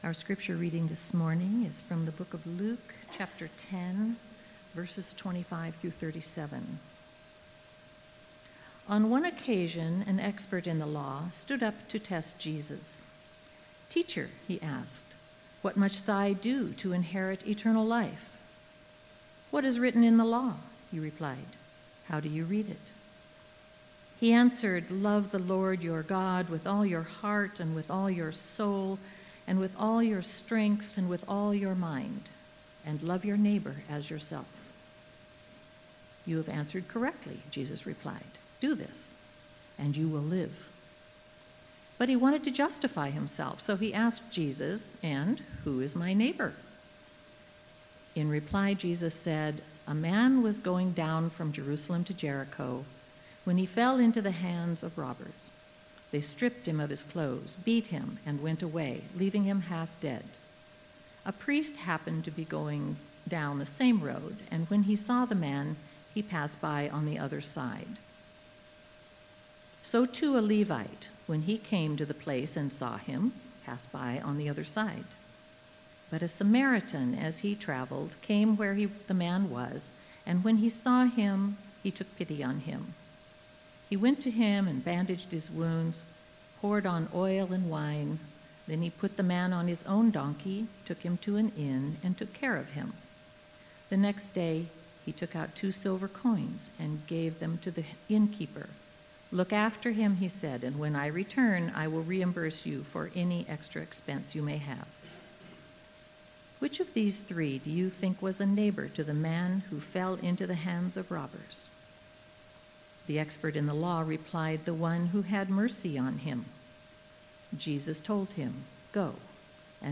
Bible Text: Luke 10.25-37 | Preacher